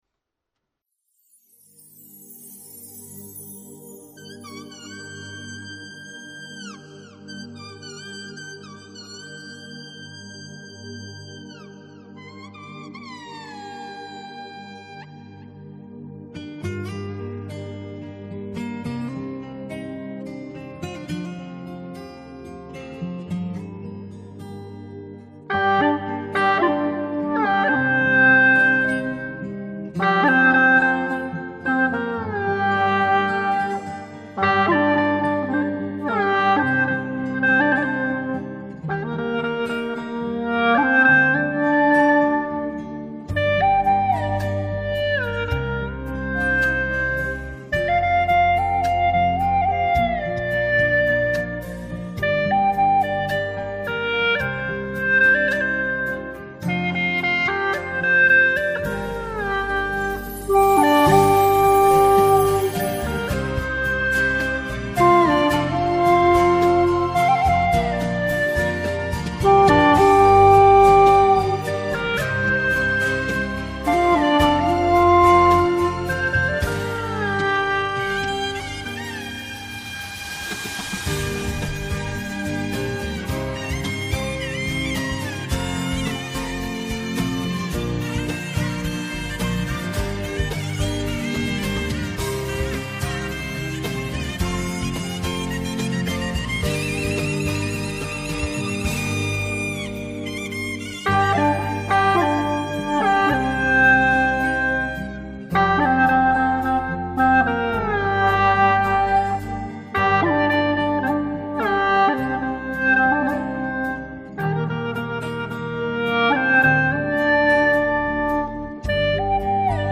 3分19秒 调式 : 降B 曲类 : 流行
如此有个性的作品，用葫芦丝演奏出来，是这种味道。。。